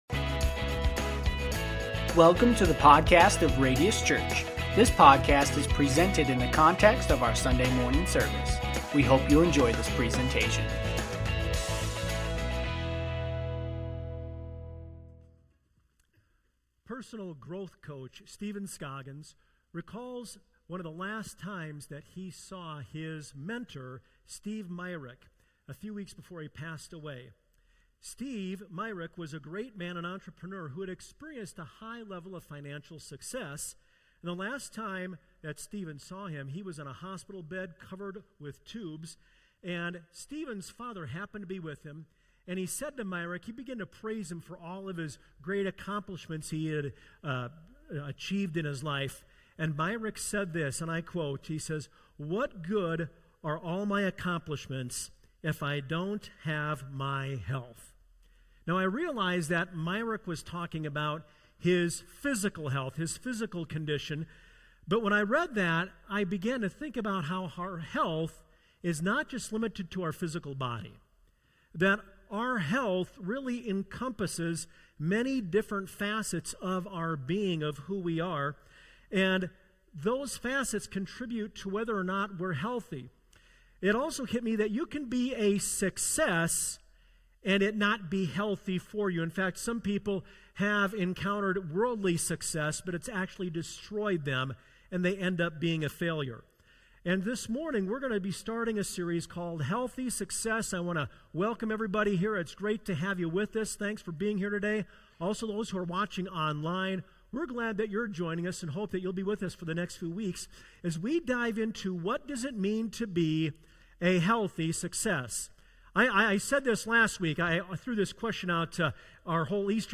Sermons | Radius Church